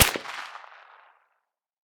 med_crack_02.ogg